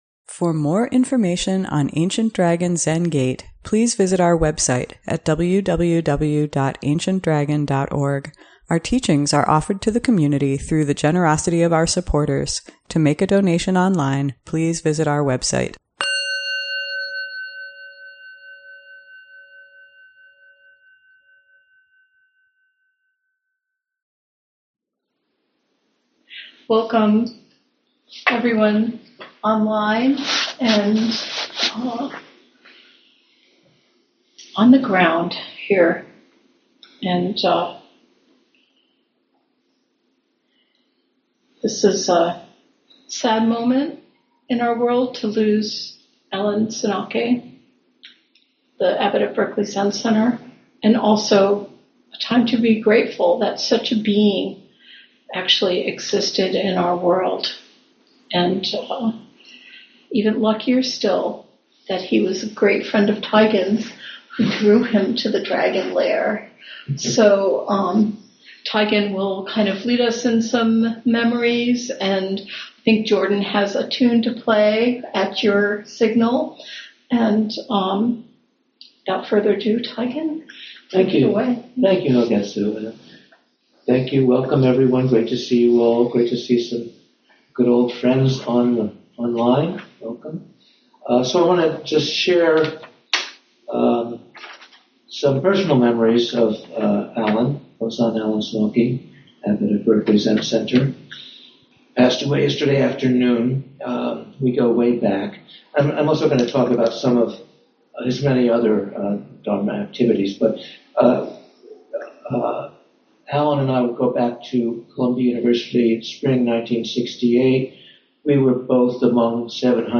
ADZG Monday Night Dharma Talk